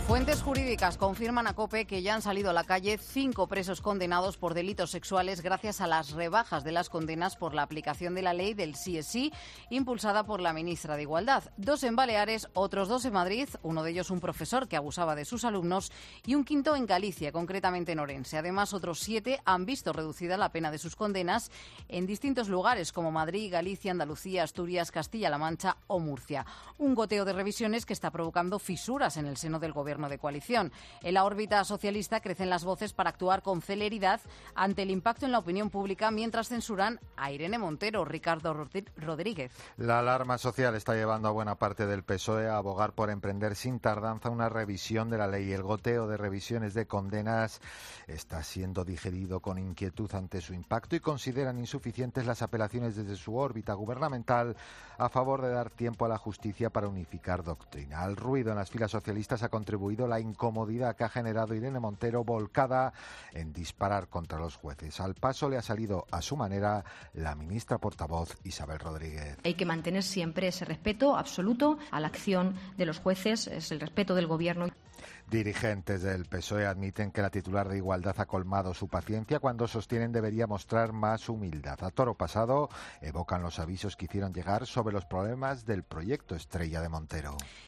La ley del 'Solo sí es sí' vuelve a causar una fractura en el seno del Ejecutivo. Informa